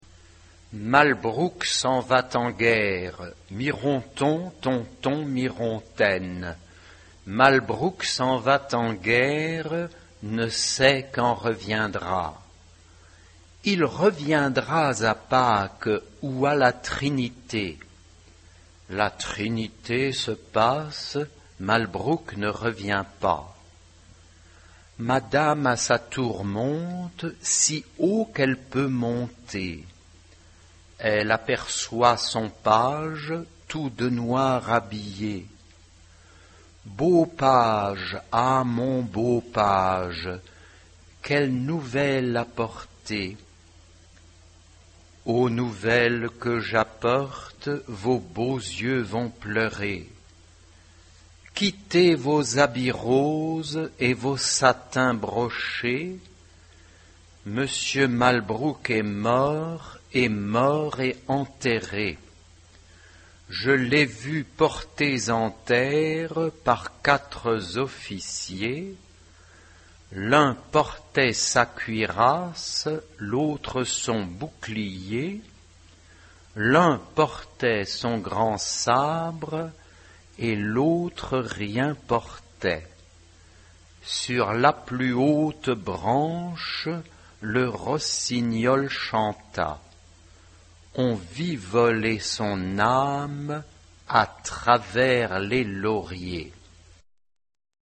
SMC (3 voces iguales).
Marcha.